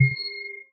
asus_sound_charging.ogg